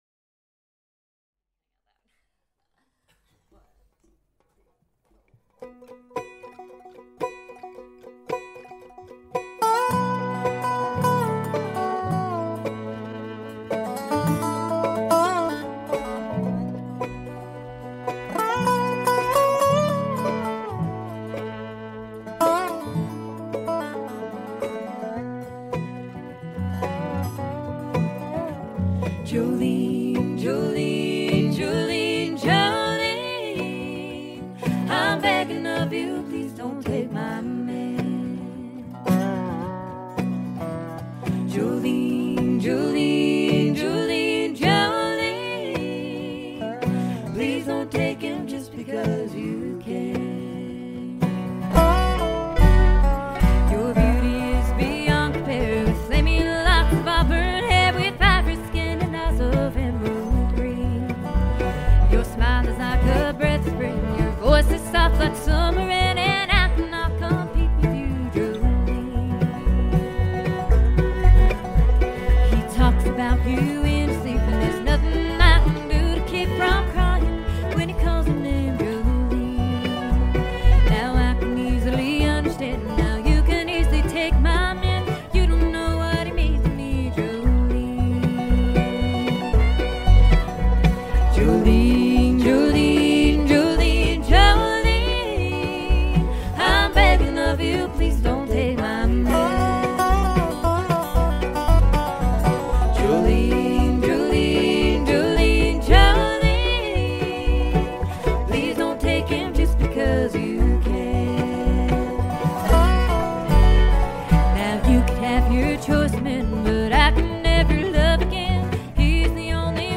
Country music
Жанр: Bluegrass